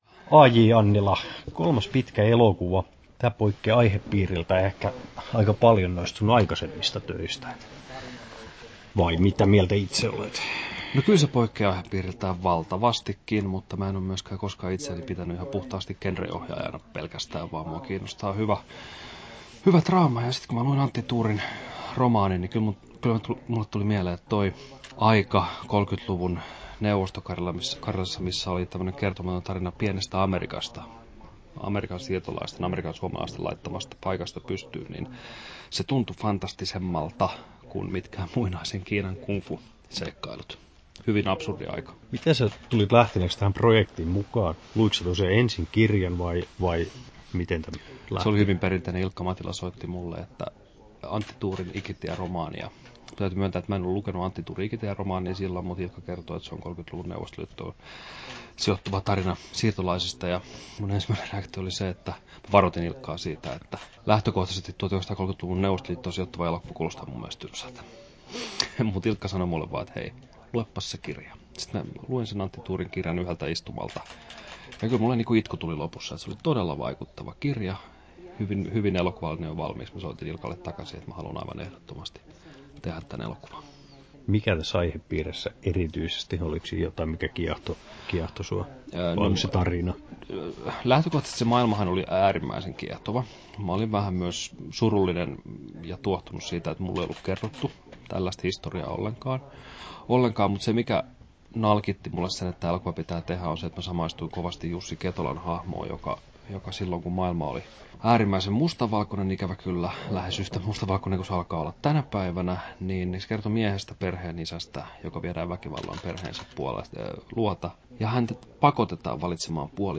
Haastattelussa AJ Annila Kesto: 6'52" Tallennettu: 24.08.2017, Turku Toimittaja